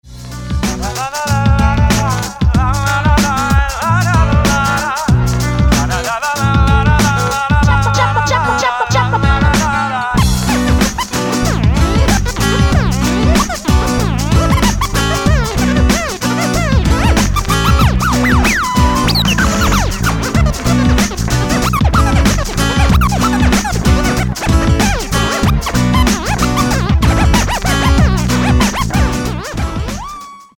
• Качество: 192, Stereo
гитара
мужской голос
Хип-хоп
электронная музыка
битбокс